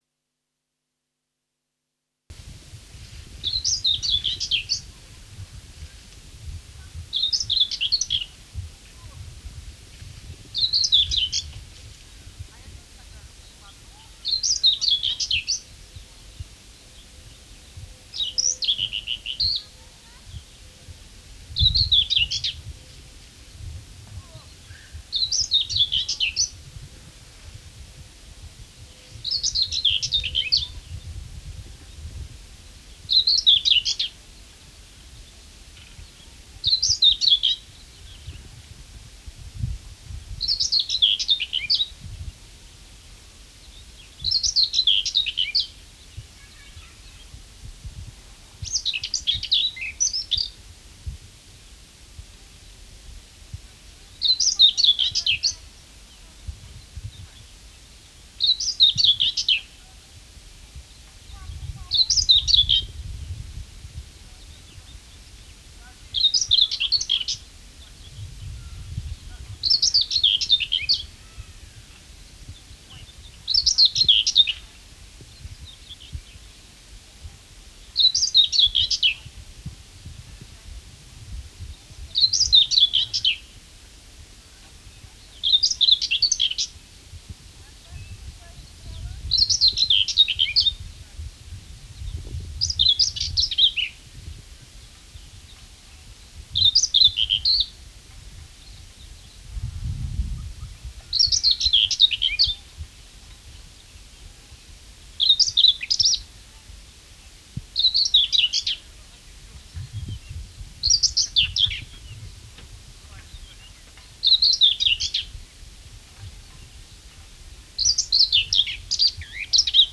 Голос ушедший весны. Записано 29 - 30 мая в НСТ " 17 разьезд", общество "Глобус". Это под Оренбургом.
Моно звучание.